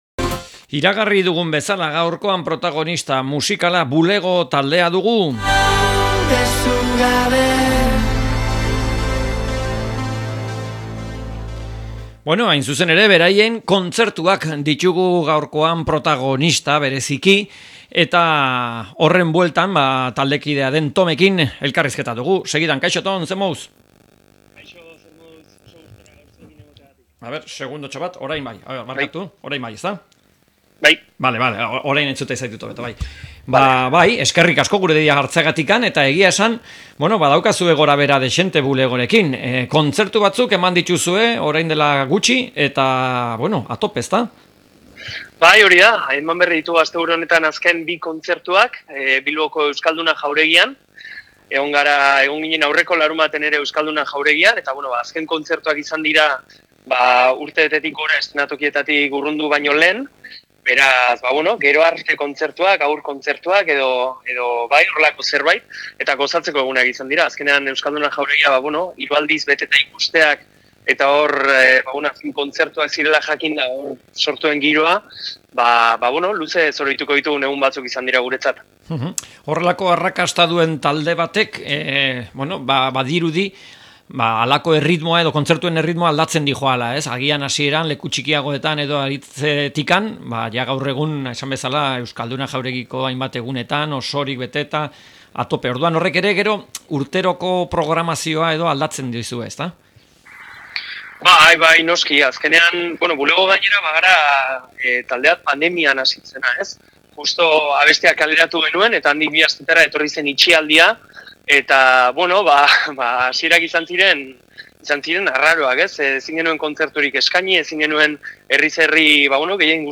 Bulego taldeari elkarrizketa